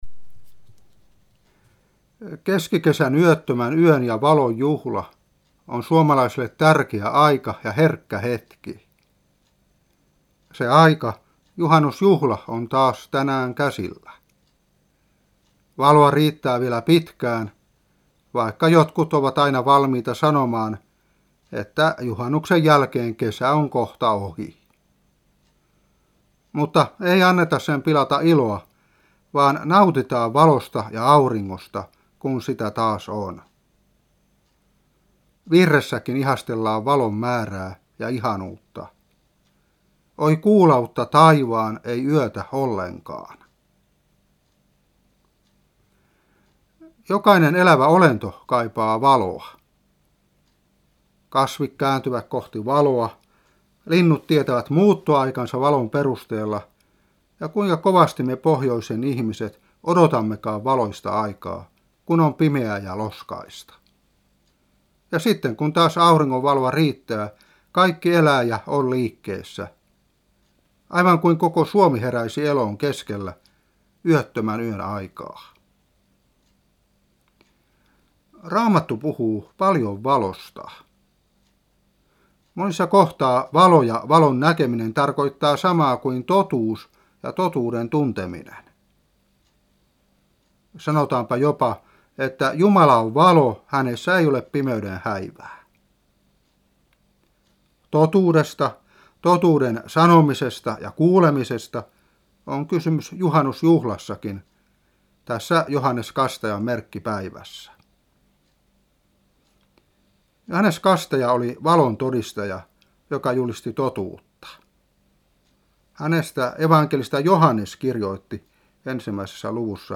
Saarna 1998-6.